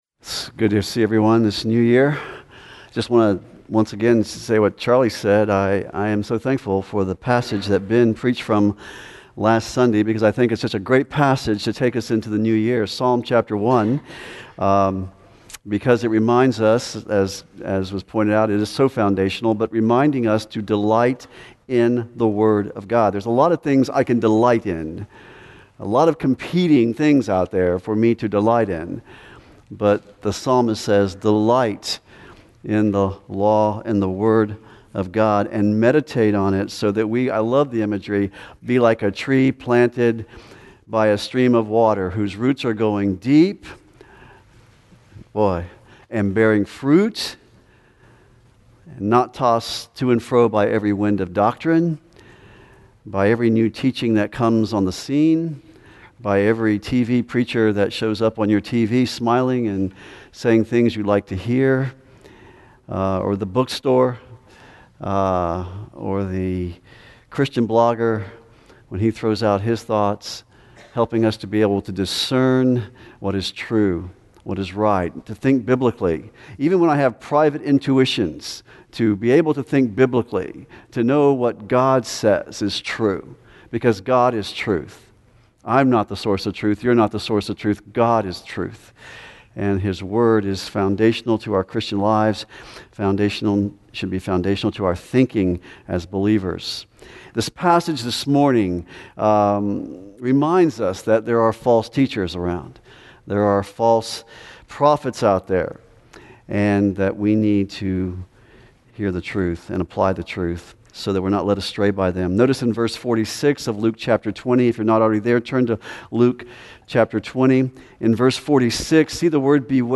Woe to You, Scribes and Pharisees - Grace Church of Tallahassee